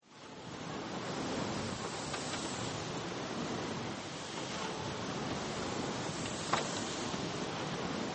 Moulin à vent , rotation rythmique ailes extérieur plan mi-proche
cracquements légers structure bois moulin , rythme évolue avec le vent ,qqs oiseaux
Mono